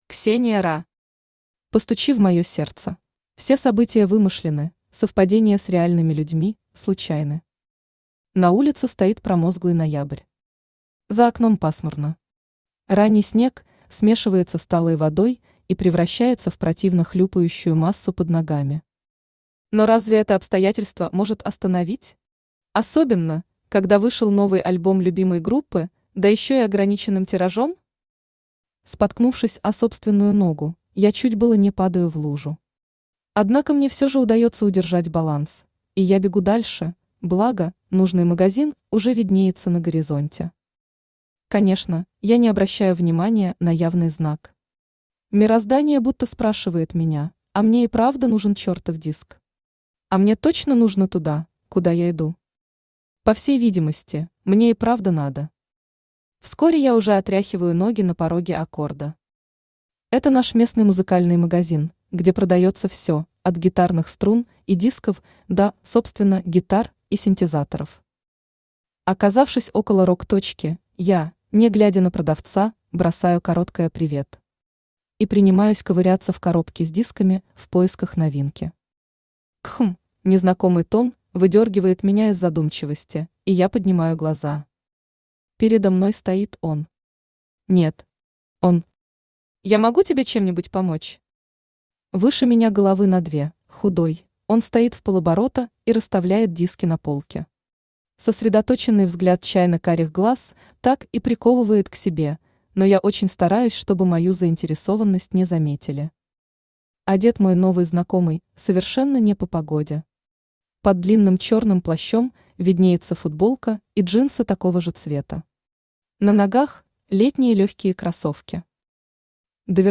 Aудиокнига Постучи в моё сердце Автор Ксения Ра Читает аудиокнигу Авточтец ЛитРес. Прослушать и бесплатно скачать фрагмент аудиокниги